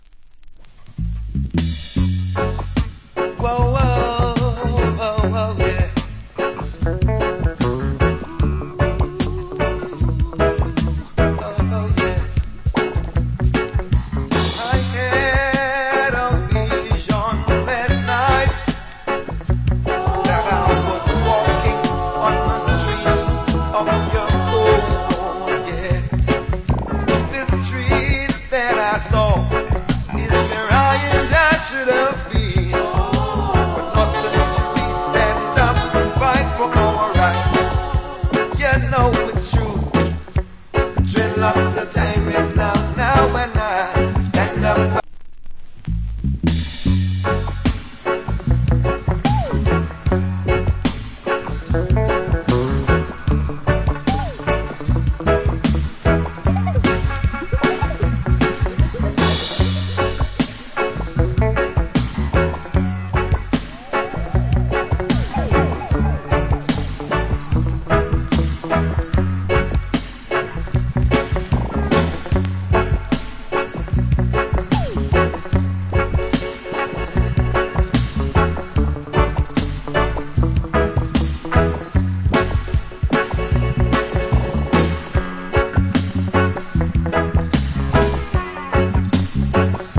Wicked Roots Rock Vocal.